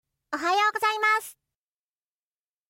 📌 Formal & Polite → This is the standard and most polite way to say “Good morning” in Japanese.